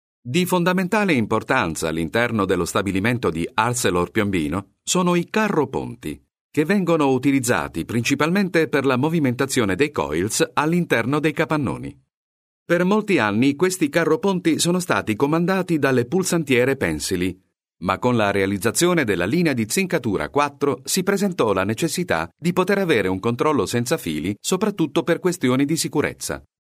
La sua voce, timbrica chiara e raffinata ma "warm", è indicata per promozioni commerciali, istituzionali aziendali, video naturalistici e audio per siti web, sempre con il miglior e più impatto comunicazionale
Sprecher italienisch mit einer äußerst präsenten italienischen Stimme.
Sprechprobe: Werbung (Muttersprache):